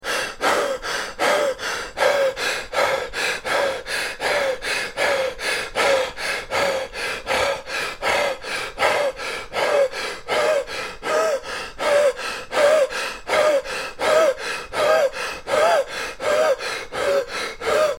Звуки запыхавшегося человека
Мужской голос тяжело дышит после бега